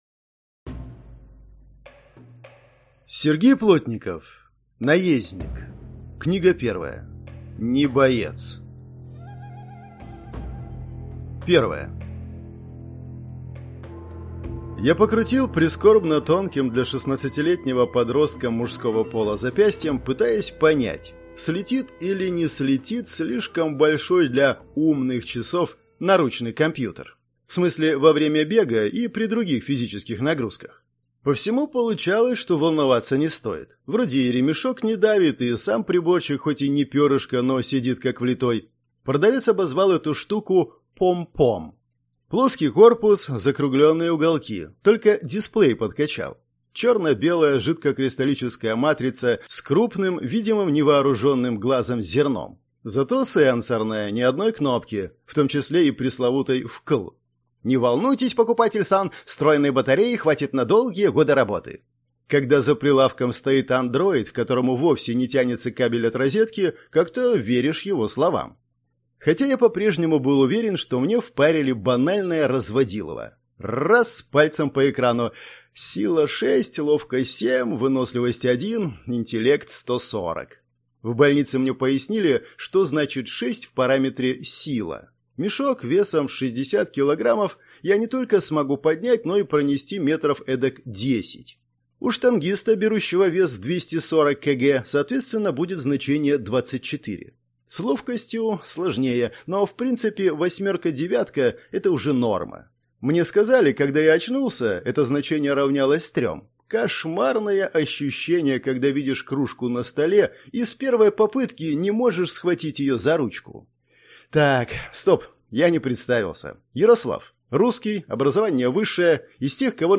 Аудиокнига Не боец - купить, скачать и слушать онлайн | КнигоПоиск